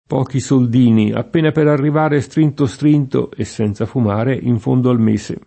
p0ki Sold&ni, app%na per arriv#re Str&nto Str&nto, e SS$nZa fum#re, in f1ndo al m%Se] (Civinini)